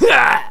death_2.ogg